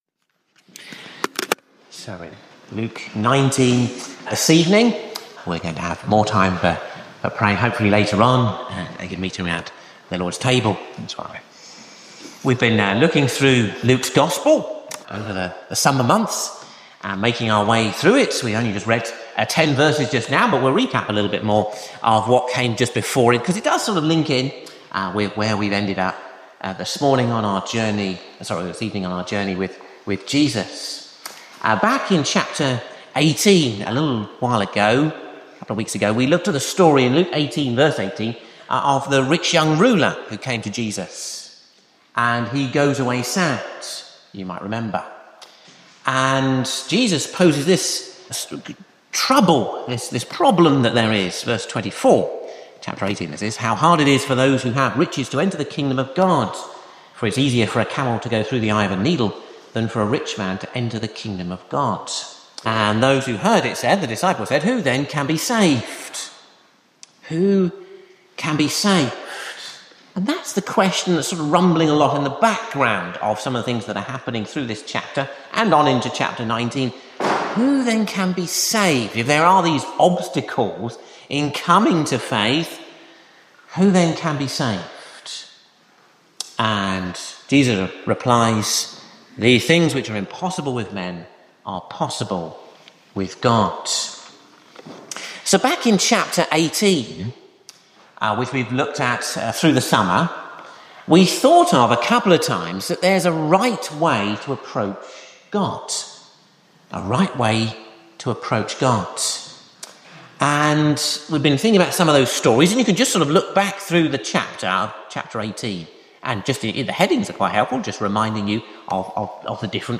For the time being we will show our most recently recorded sermon or service here.